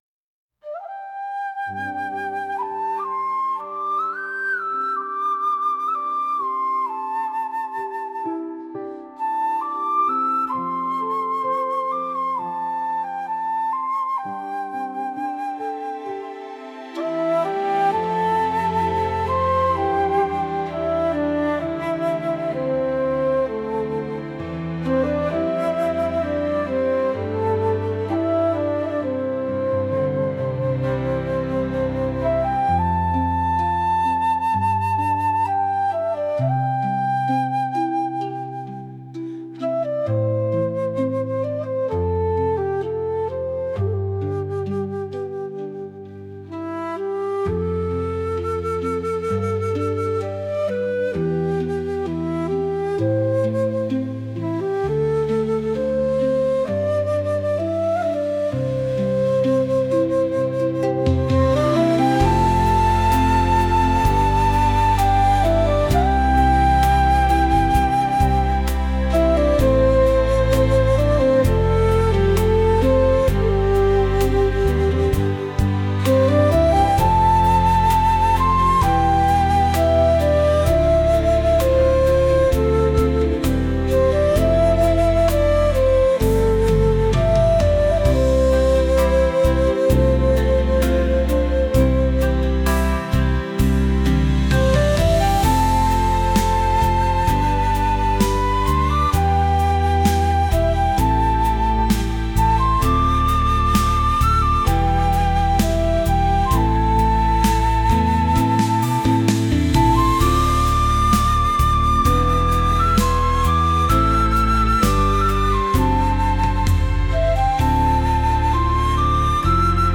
篠笛メインの歴史を感じさせるような曲です。